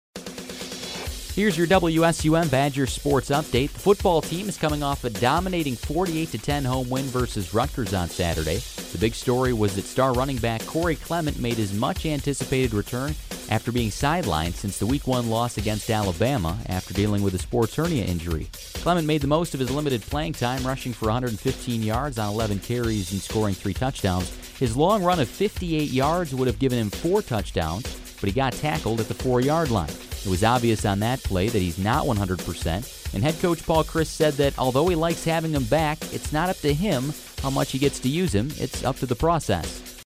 Best Audio Sports Reporting
Best-Sports-Reporting.mp3